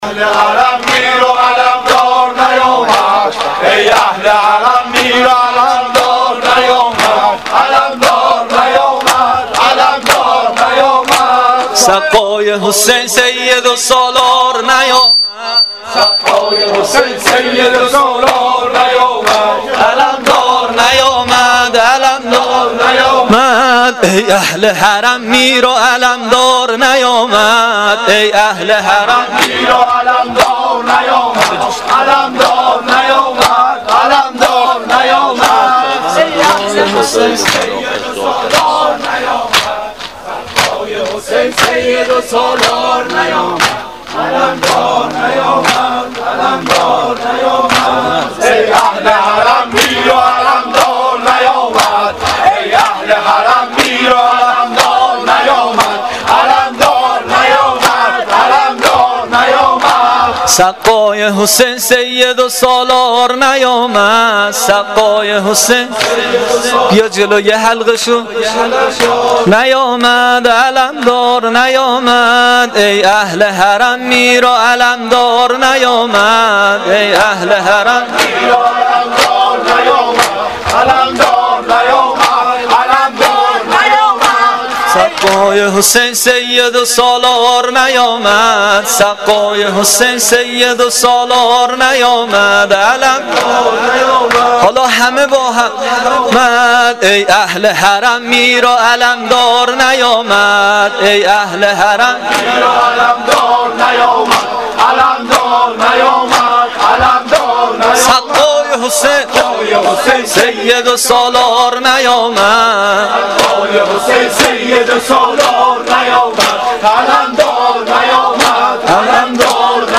دودمه شب نهم محرم الحرام 1396 (شب تاسوعا)
دو دمه
روضه